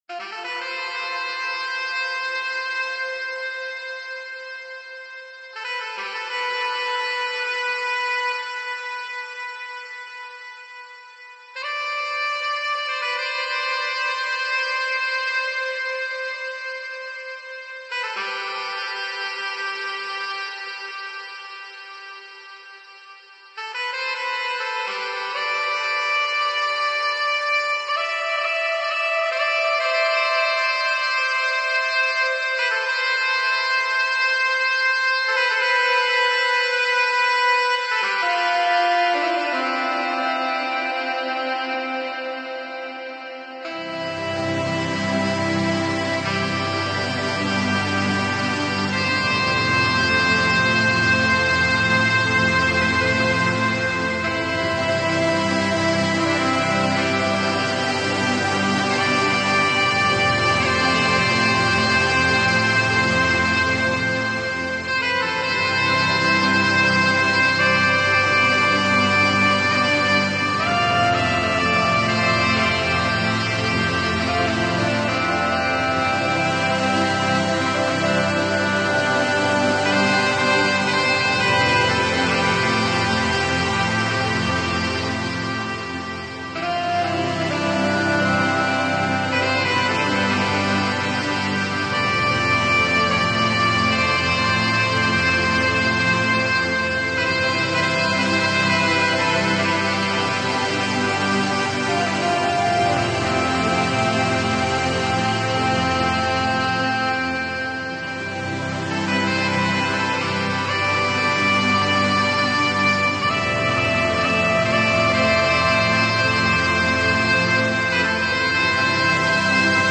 音乐流派：Chillout, Lounge, Bossa